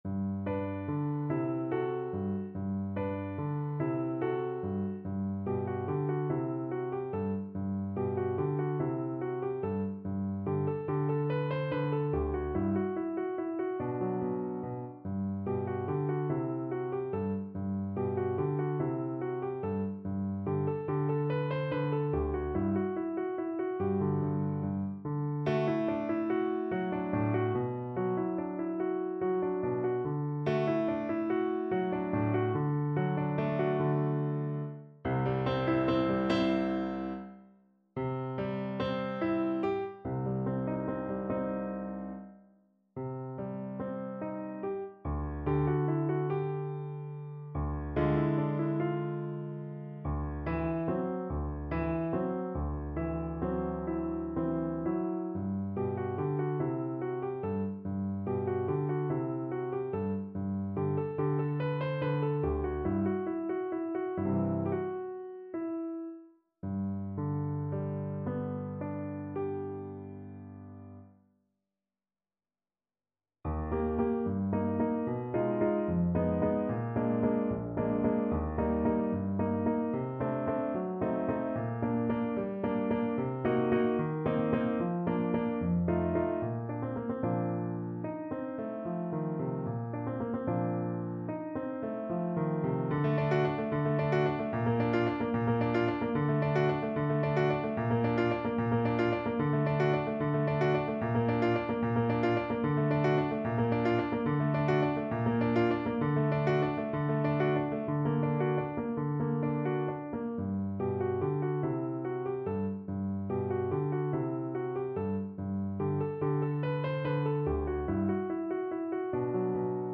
Delibes: Duet kwiatów (na wiolonczelę i fortepian)
Symulacja akompaniamentu